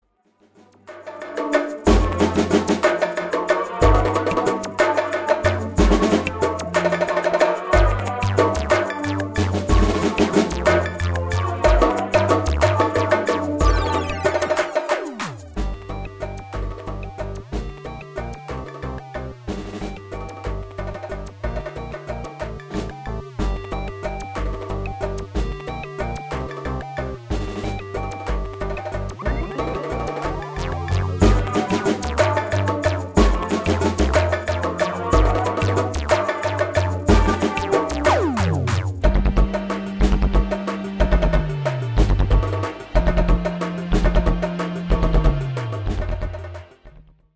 Voicing: Multiple P